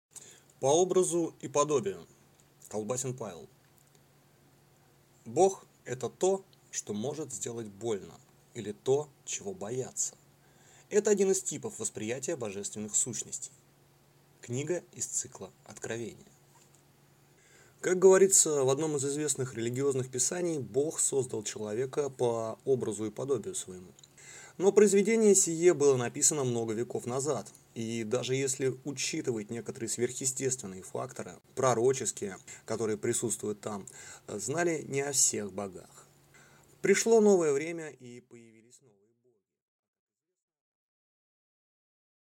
Аудиокнига По образу и подобию | Библиотека аудиокниг